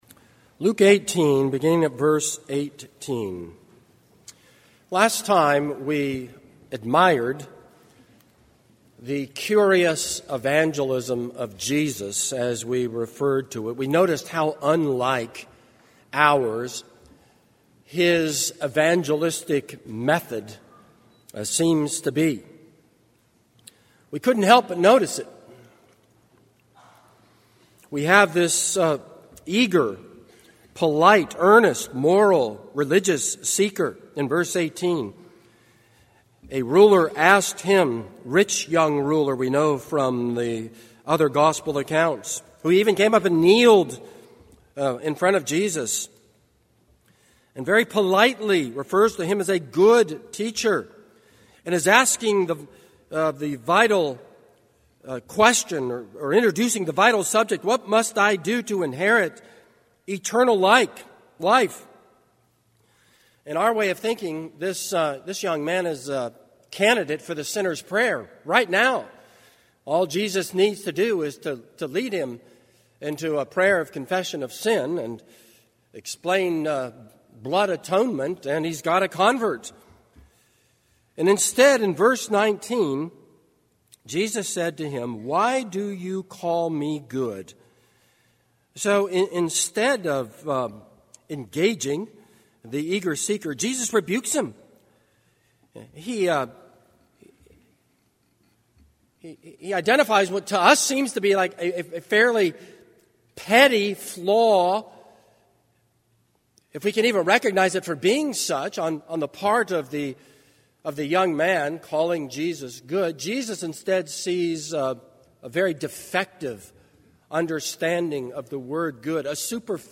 This is a sermon on Luke 18:18-27.